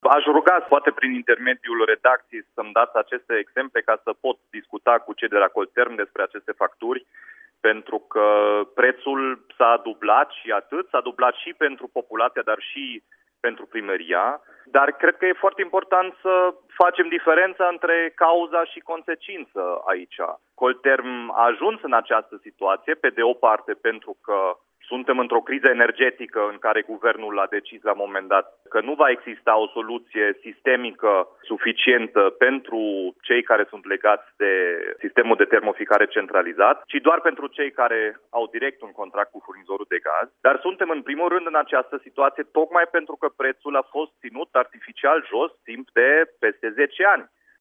Precizarea a fost făcută, după ce edilului i-au fost prezentate câteva cifre în direct la Radio Timișoara: dacă în ianuarie, la un apartament cu o cameră, factura venise 120 de lei, în februarie a ajuns la 480 de lei, iar la un apartament cu 4 camere din casă veche, factura a crescut de la 327 la 1.100 de lei.